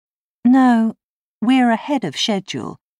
それに対して女性が、こう返事をしました。
理由は、「スケジュール」が「シェジュー」 と発音されているからです。